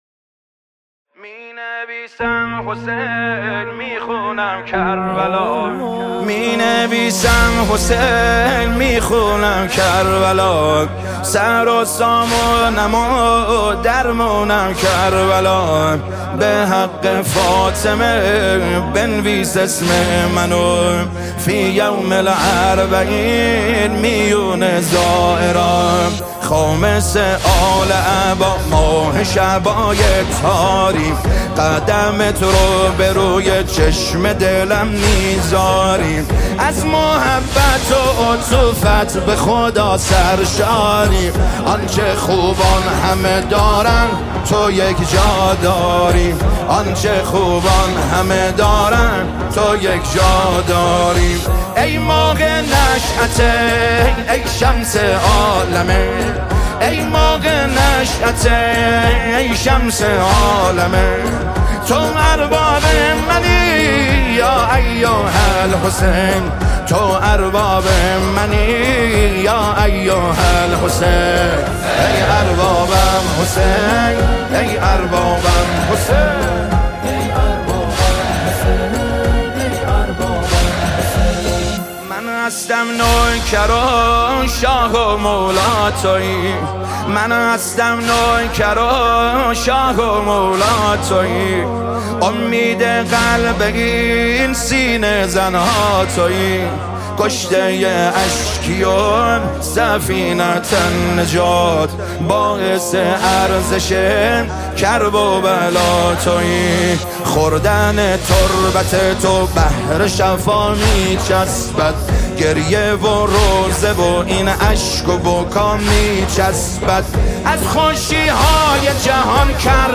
روضه و مرثیه ها